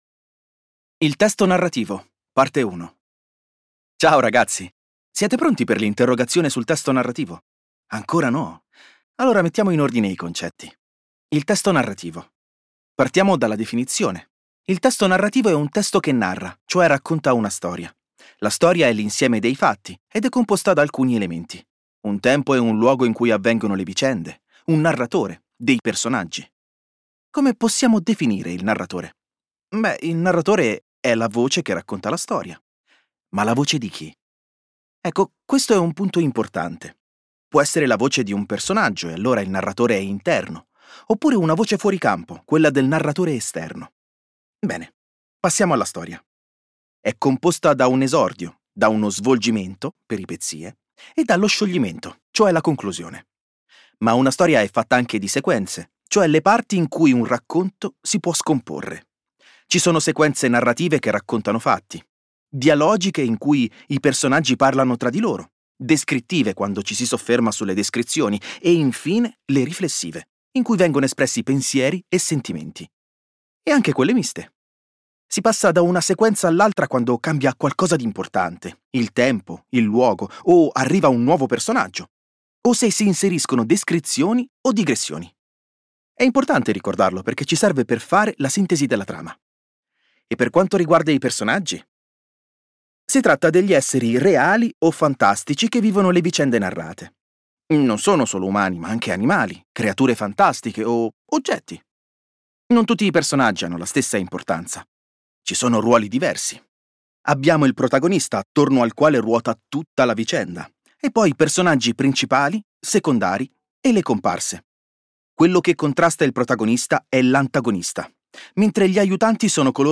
Il contenuto della lezione è disponibile anche in formato audio.